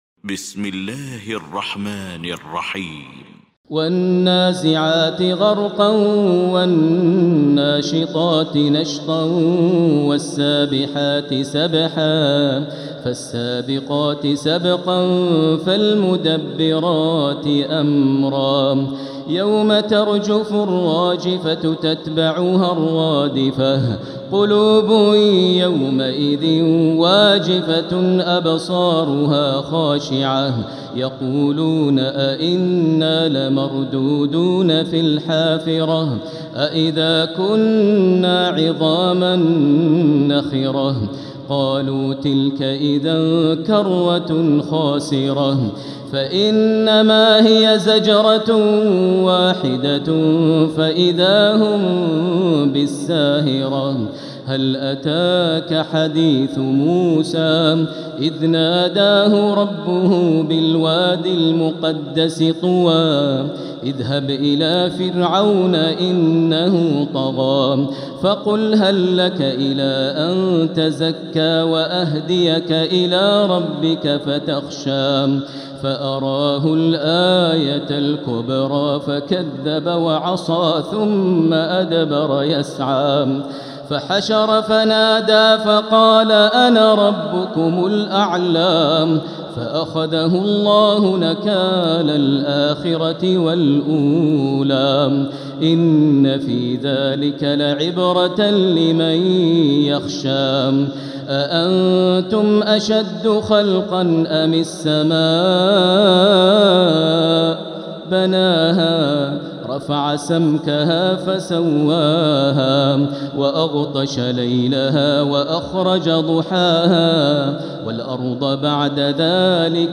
المكان: المسجد الحرام الشيخ: فضيلة الشيخ ماهر المعيقلي فضيلة الشيخ ماهر المعيقلي النازعات